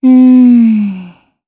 Xerah-Vox_Think_jp.wav